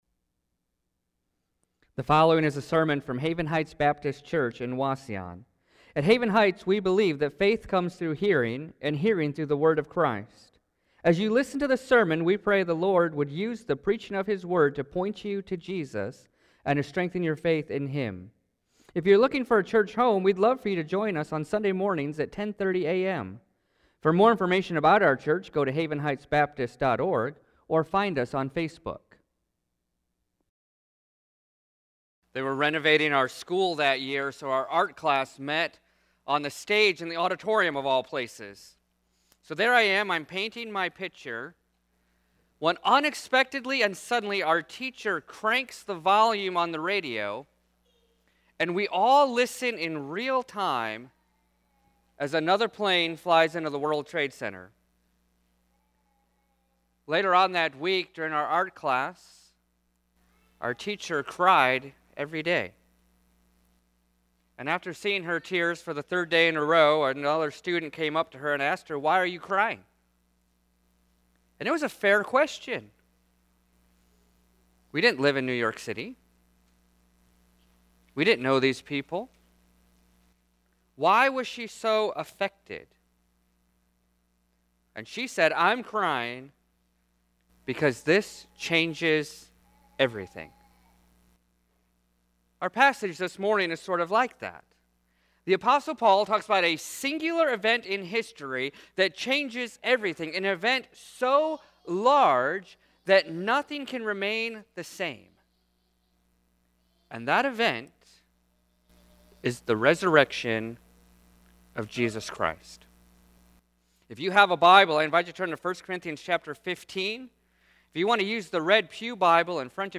Sermons | Haven Heights Baptist Church